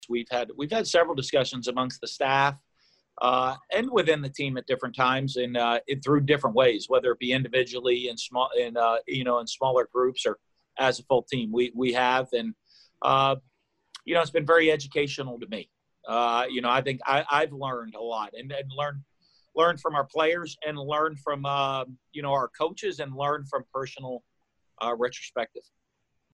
Head coach Dan Mullen sat down with reporters in a Zoom press conference to discuss Gator football.